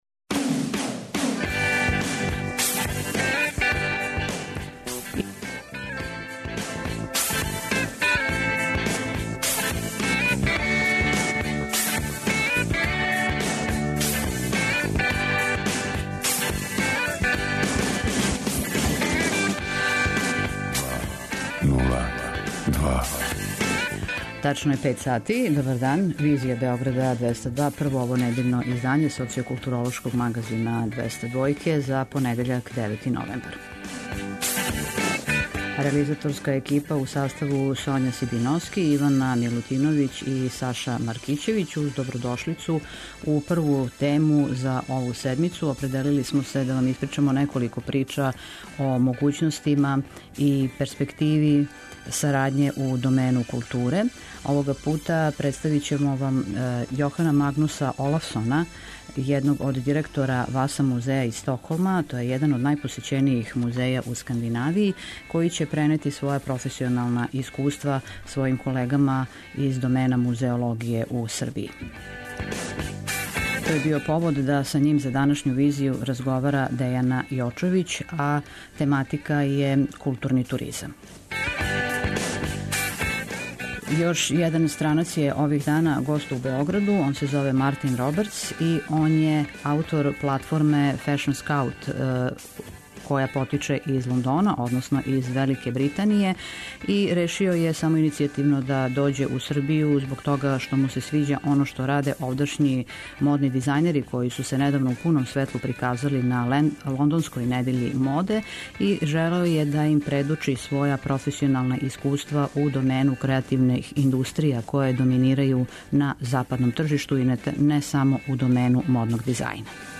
За данашњу „Визију" говоре међународно признати стручњаци из области културе: музеологије и модног дизајна.
преузми : 55.61 MB Визија Autor: Београд 202 Социо-културолошки магазин, који прати савремене друштвене феномене.